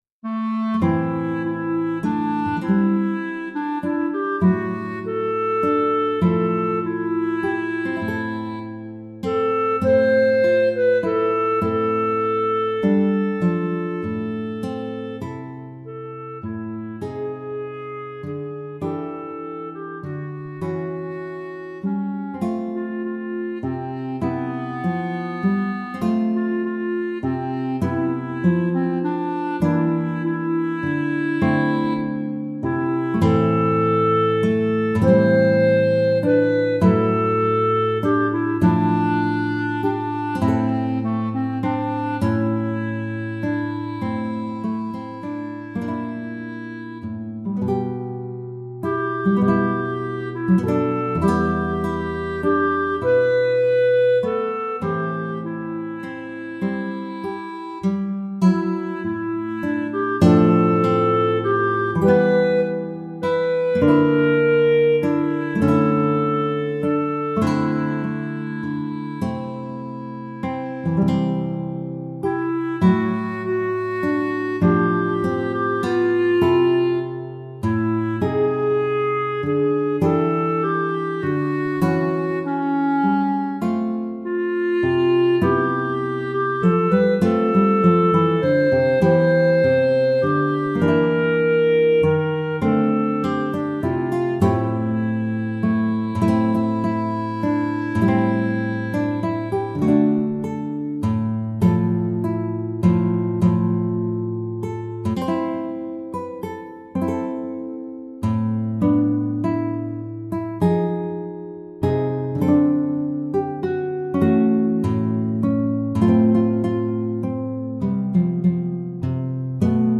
Sad duo for clarinet and guitar
Electronic preview